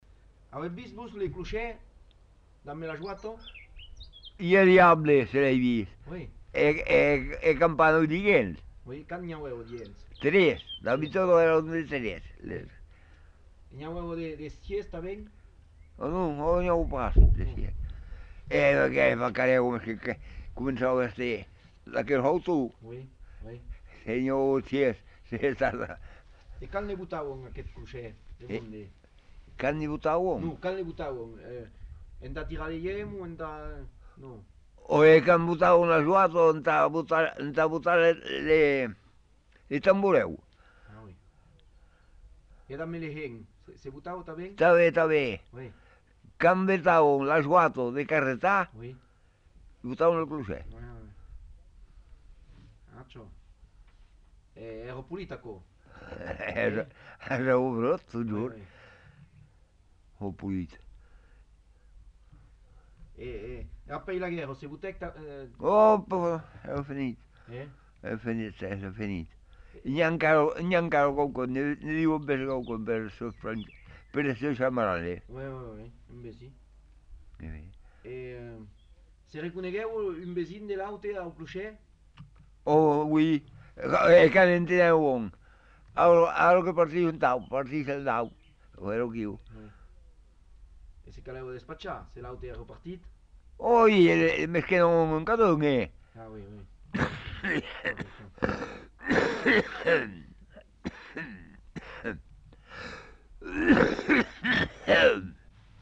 Lieu : Montadet
Genre : témoignage thématique